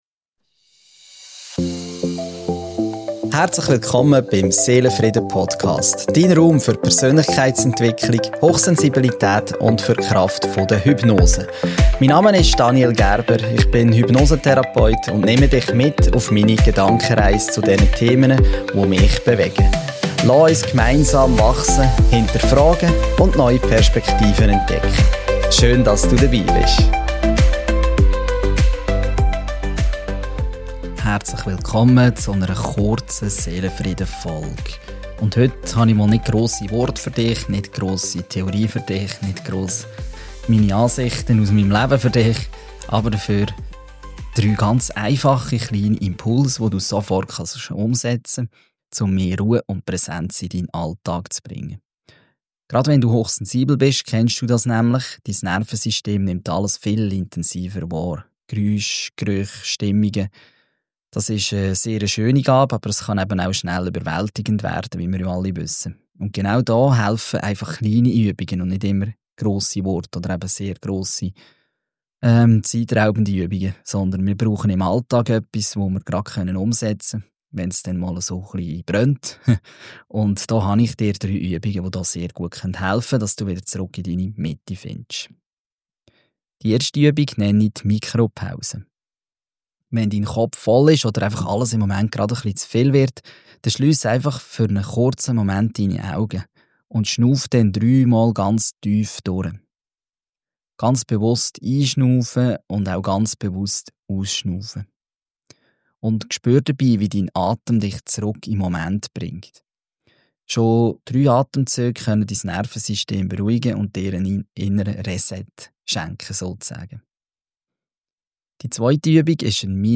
Die im Podcast verwendete Musik stammt von